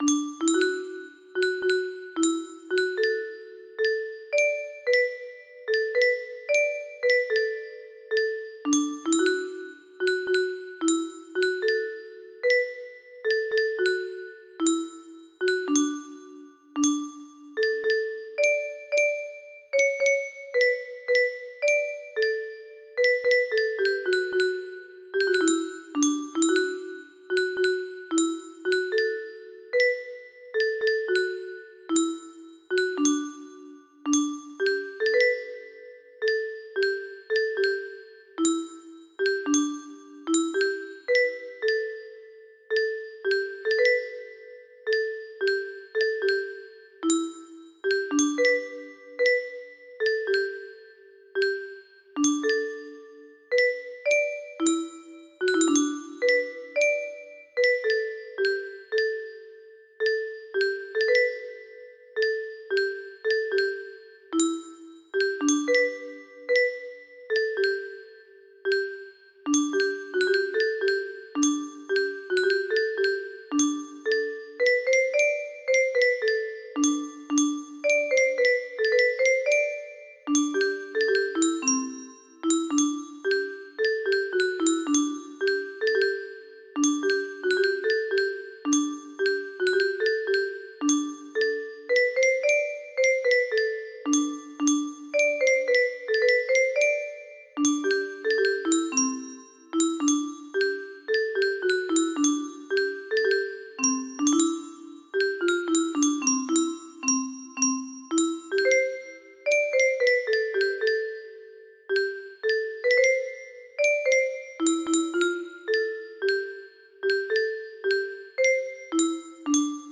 The Rowan Tree Scottish Song music box melody